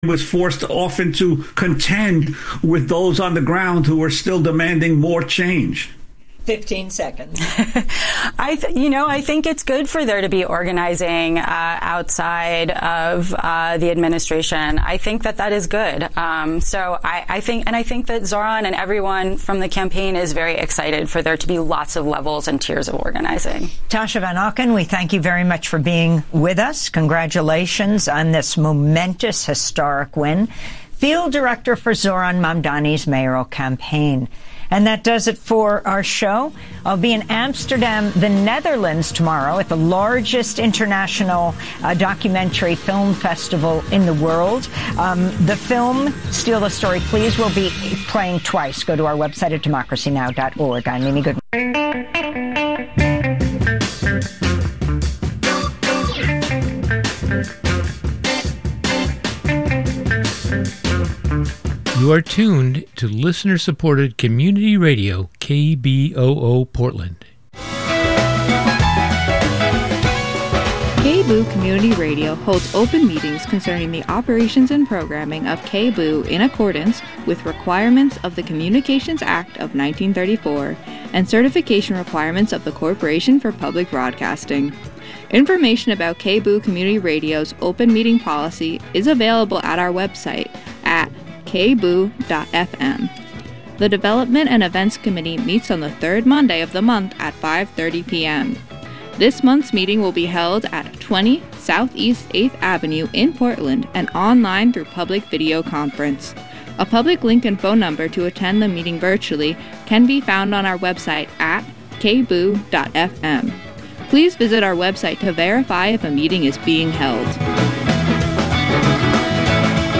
And because we're keeping you guessing by keeping things the same, we continue our conversation with callers about Lateral Thinking.